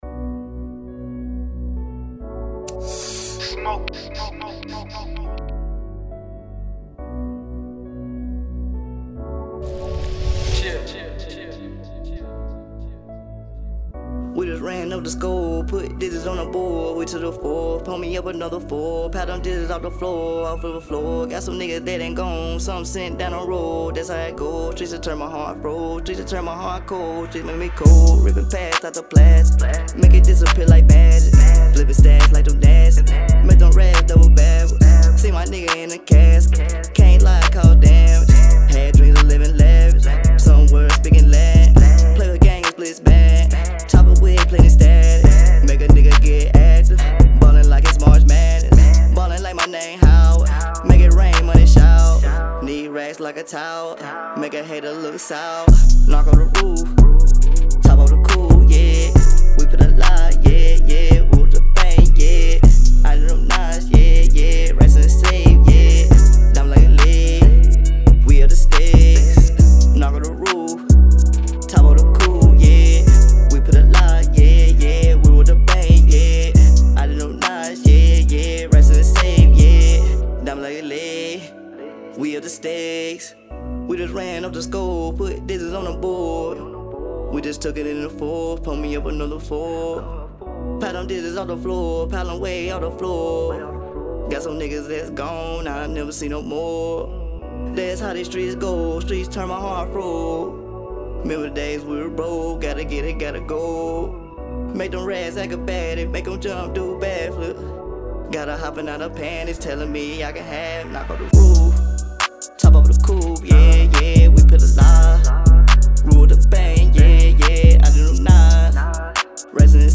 Hiphop
the beat just inspired a Triumph feel